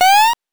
jump_5.wav